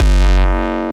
Desecrated bass hit 04.wav